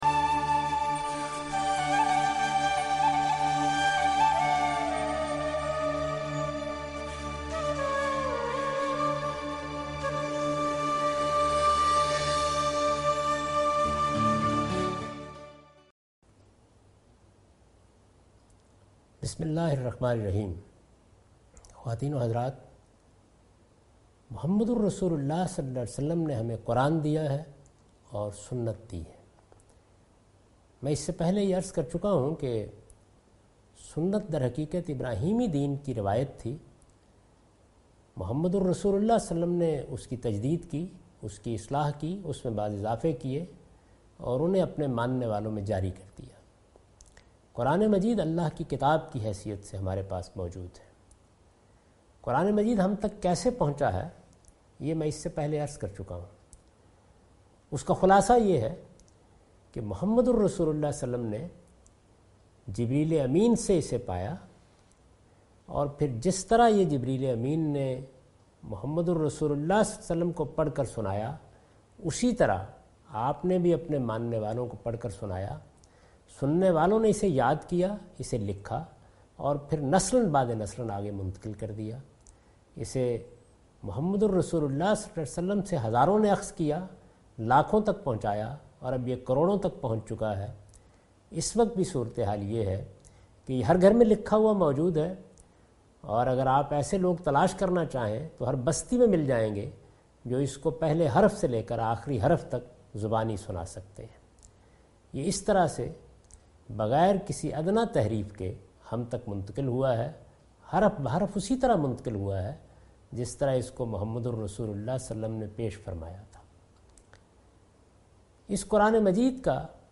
This series contains the lecture of Javed Ahmed Ghamidi delivered in Ramzan. He chose 30 different places from Quran to spread the message of Quran. In this lecture he explains the concept of groups of surahs in Quran.